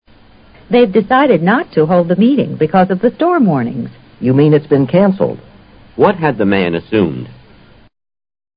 托福听力小对话【86】storm warnings
女方说因暴风雨警报而会议不开了。而男方则以为会议取消了，这自然是由于他估计暴风雨会延续一段时间。